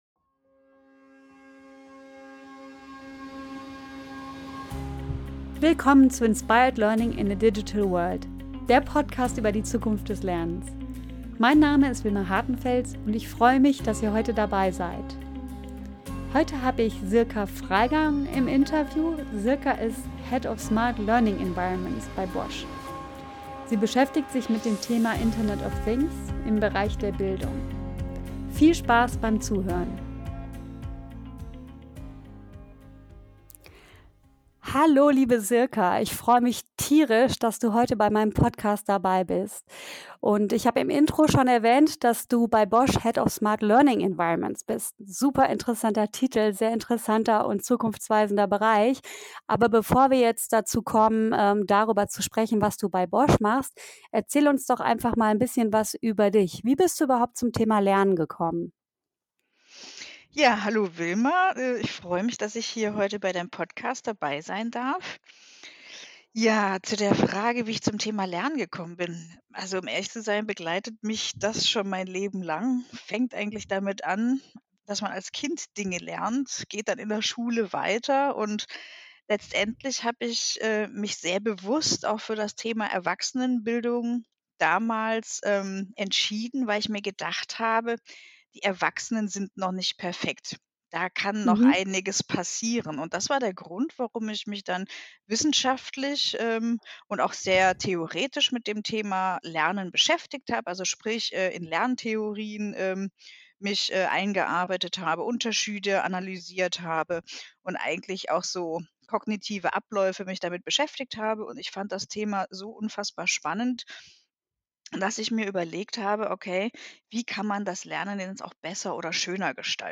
In diesem spannenden Interview sprechen wir über ihre Erfahrungen, aktuelle Projekte, Visionen & Ideen.